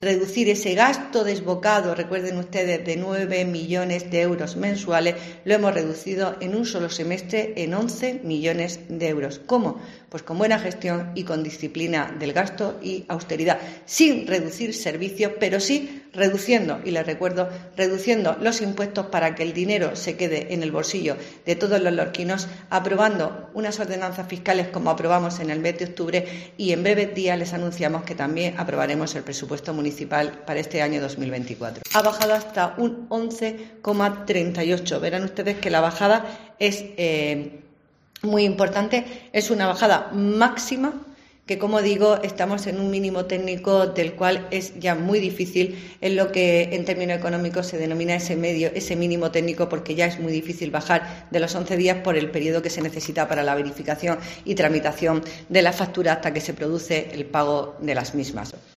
Belén Pérez, concejal Hacienda del PP